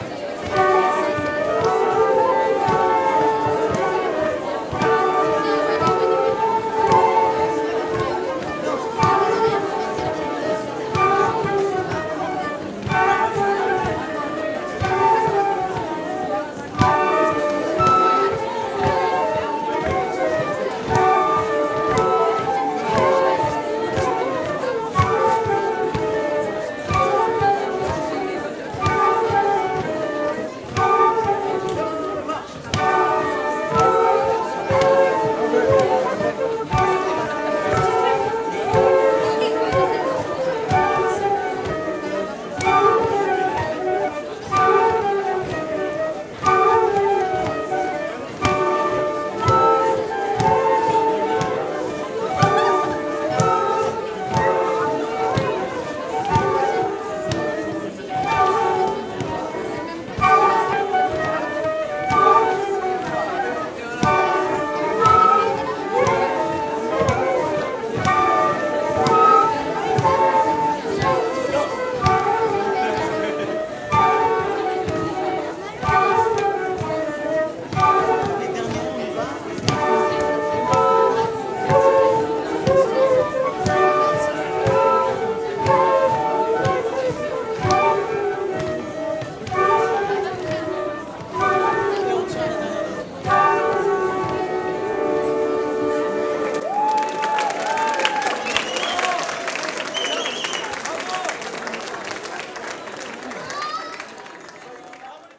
06_bal_loudia-flutes.mp3